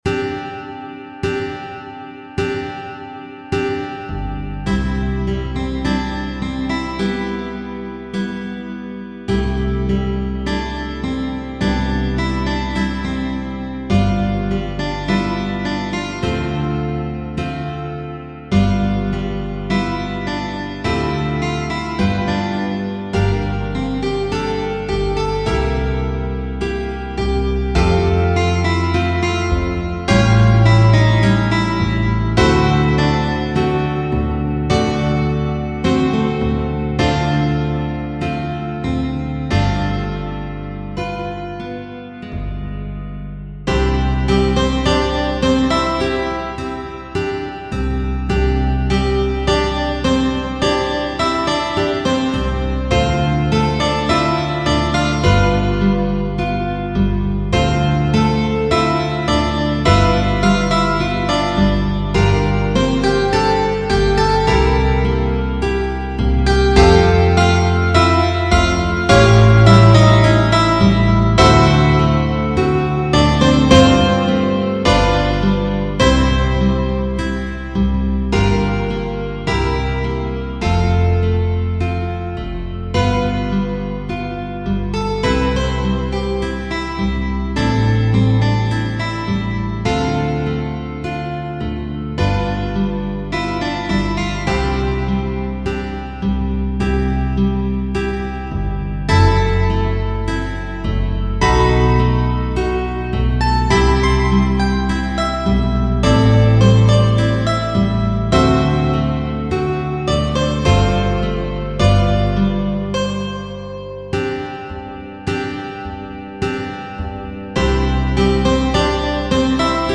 Partitur Besetzung: Zupforchester Dauer